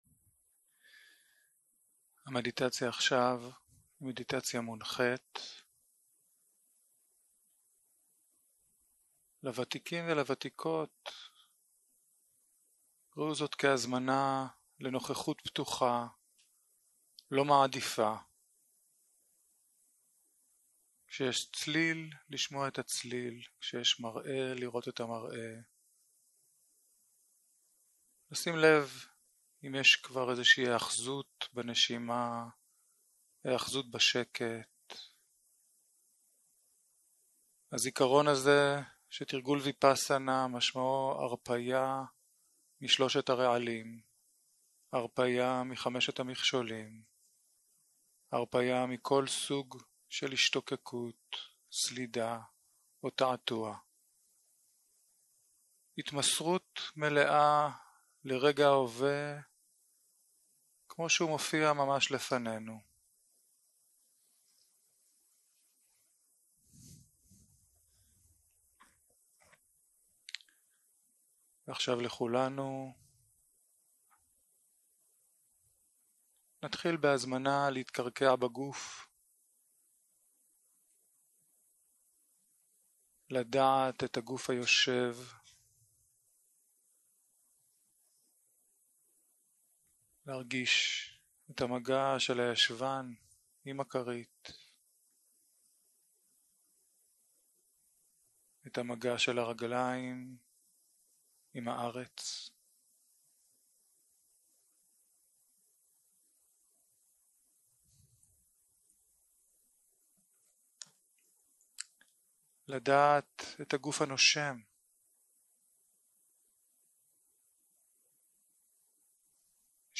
יום 2 - צהרים - מדיטציה מונחית - הקלטה 3
סוג ההקלטה: מדיטציה מונחית